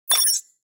دانلود آهنگ کلیک 12 از افکت صوتی اشیاء
جلوه های صوتی
دانلود صدای کلیک 12 از ساعد نیوز با لینک مستقیم و کیفیت بالا